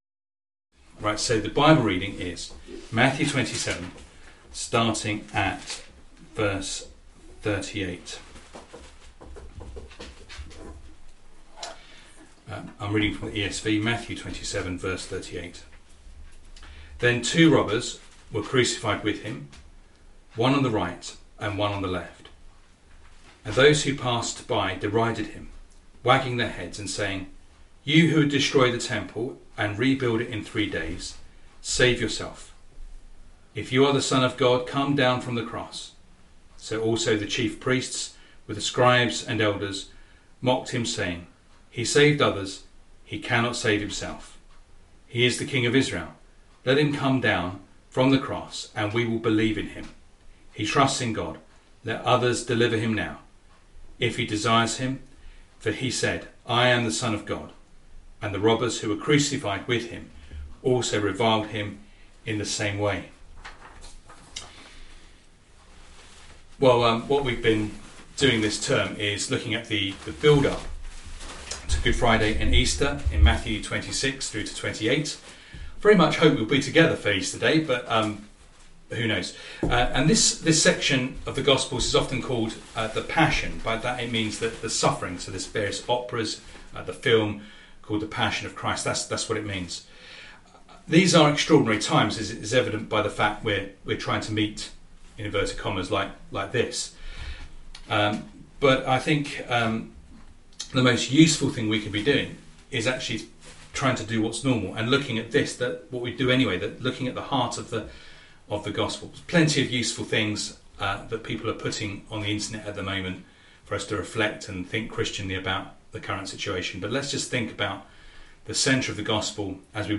Service Type: Sunday Morning Reading and Sermon